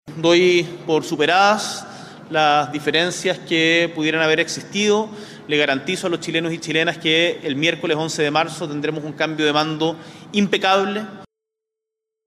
De esta manera, durante un encuentro que se extendió por casi dos horas, ambos — según señaló el mismo Presidente Boric en un punto de prensa posterior — conversaron sobre diferentes temas de interés para la próxima administración, como el funcionamiento del Sename desde 1979, el trabajo de la Comisión para la Paz y el Entendimiento, la seguridad respecto a los cable submarinos y el traspaso de mando que se avecina para este miércoles.